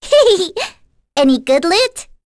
May-Vox_Victory.wav